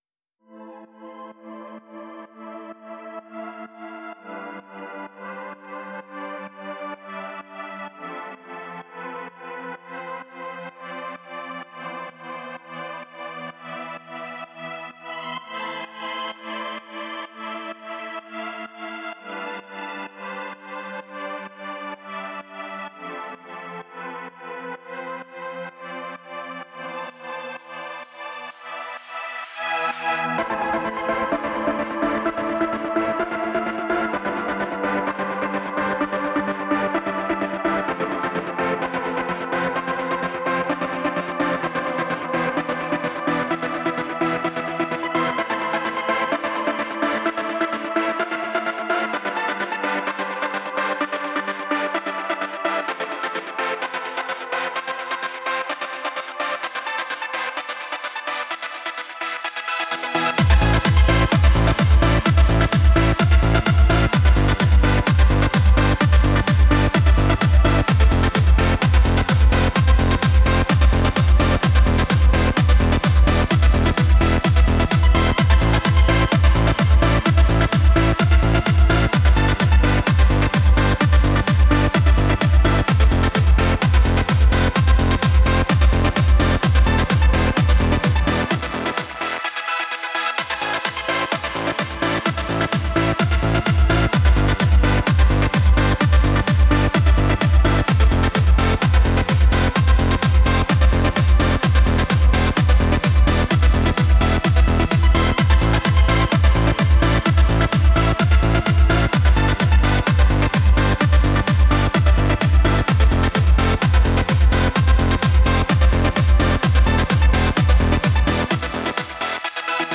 BEST ELECTRO A-F (35)